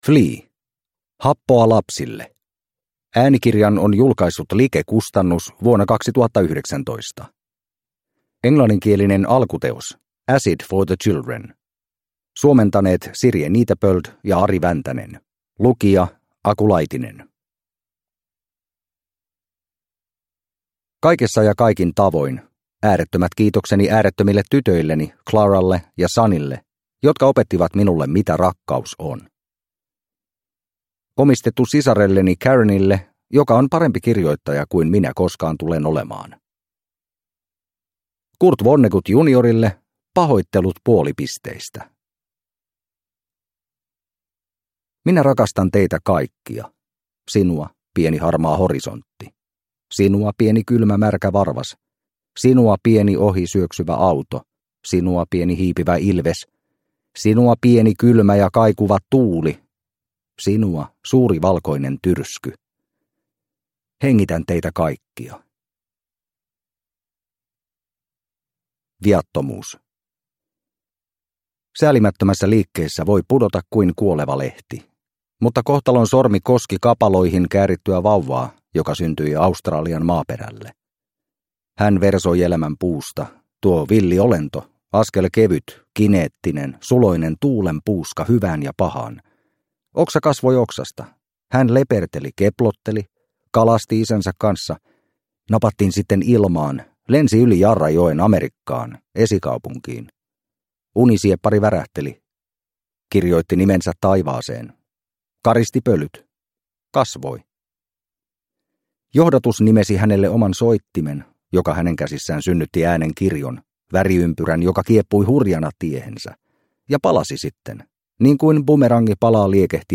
Happoa lapsille – Ljudbok – Laddas ner